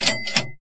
equip.ogg